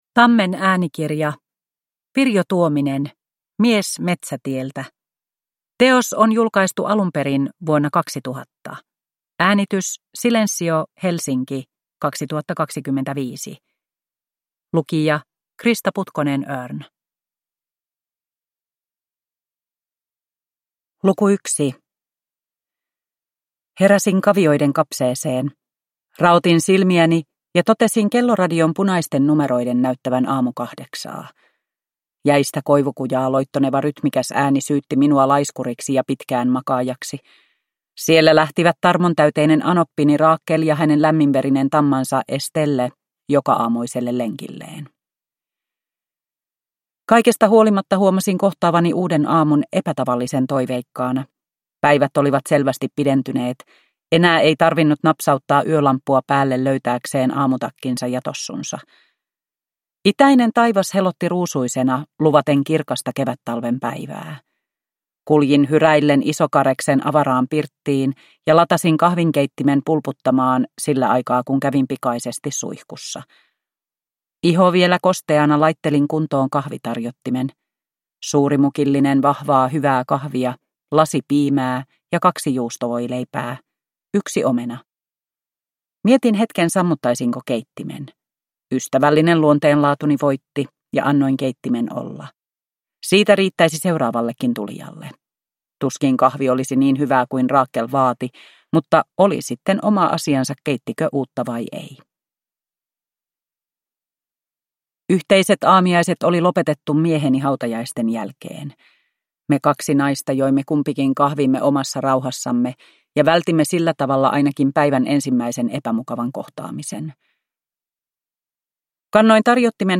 Mies metsätieltä (ljudbok) av Pirjo Tuominen